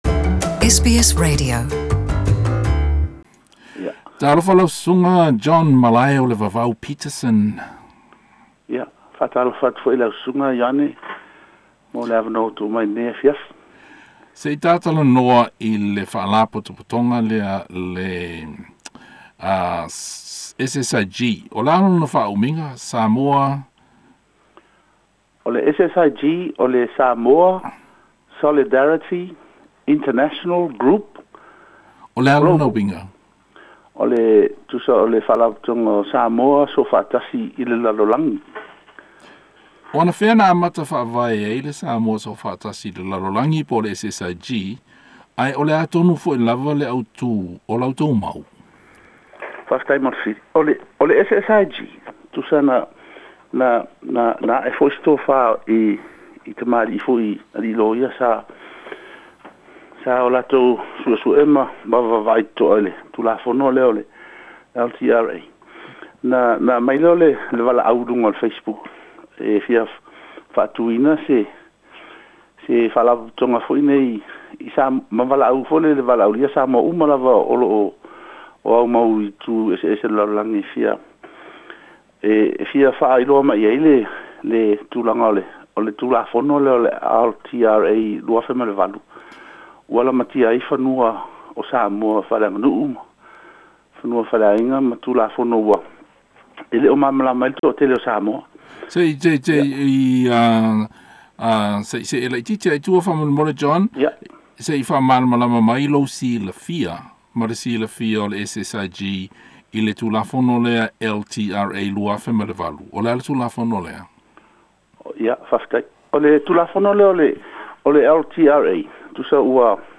Talanoaga ma le mau SSIG i Ausetalia